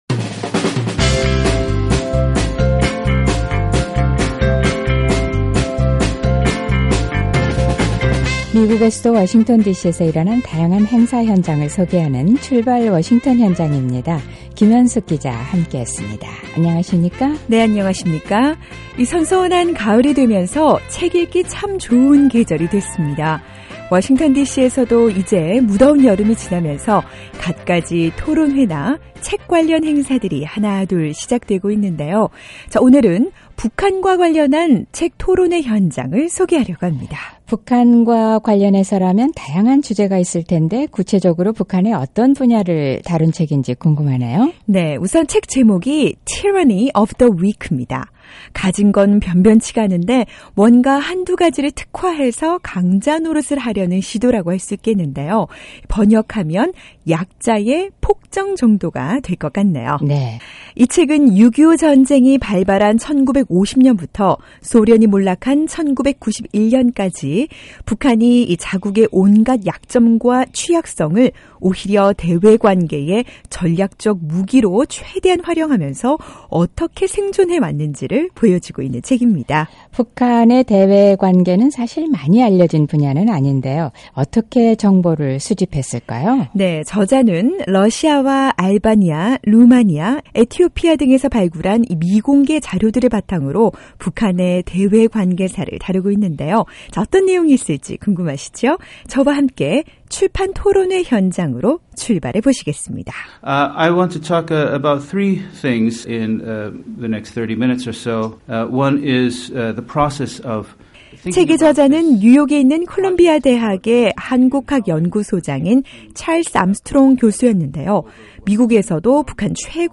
북한 외교정책 다룬 '약자의 폭정' 출판 토론회